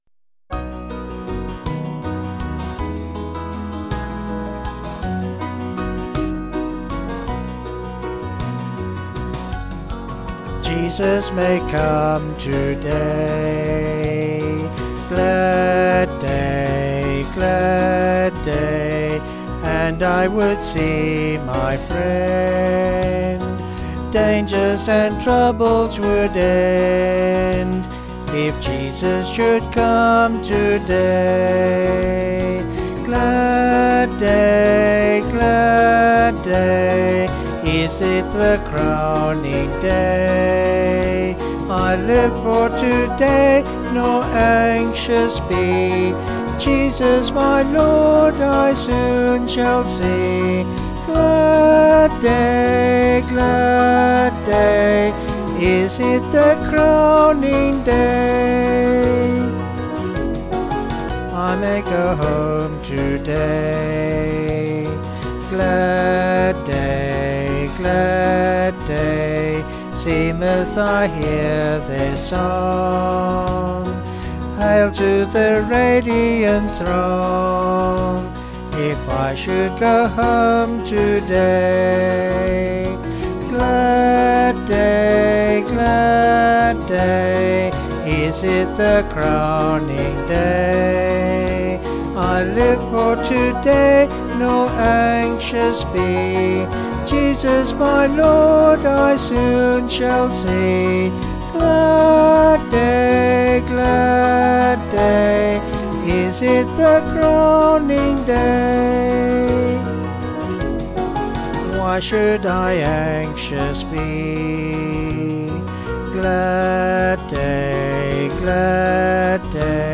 Vocals and Band   264kb Sung Lyrics